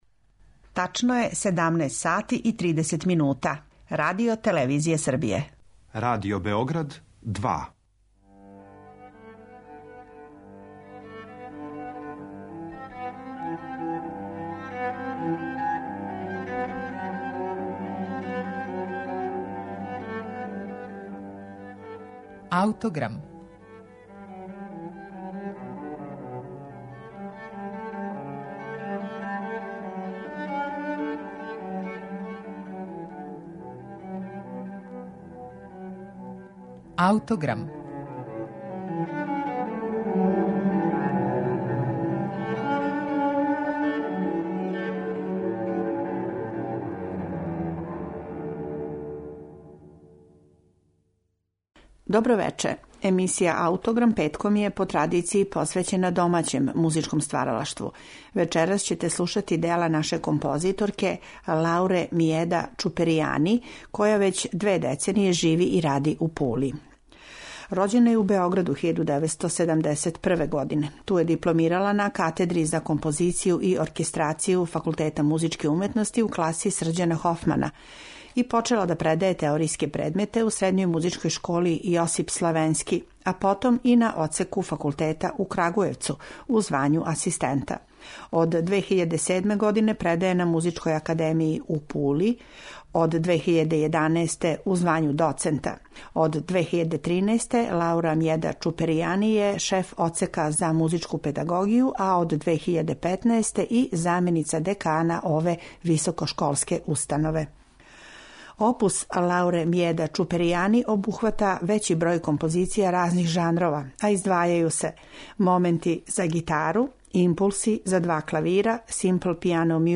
Емисију ће започети њен Концерт за гитару